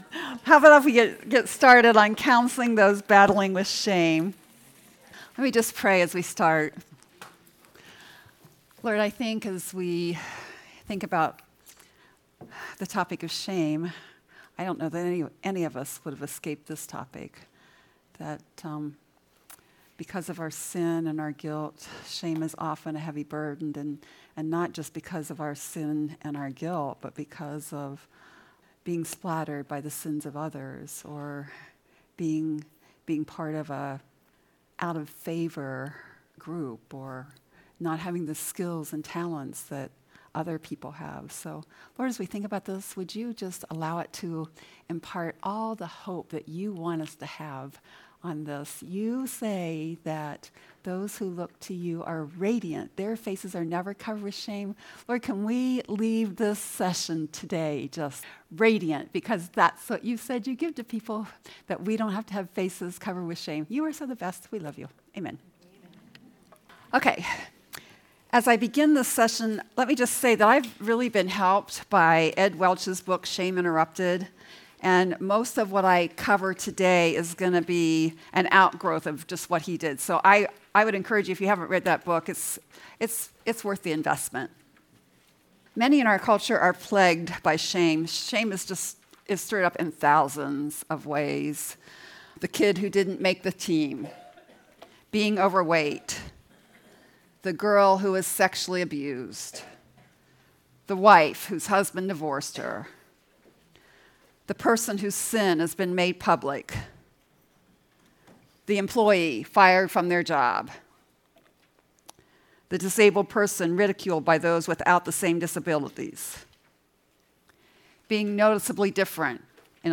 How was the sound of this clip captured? You may listen to the first 10 minutes of this session by clicking on the "Preview Excerpt" button above.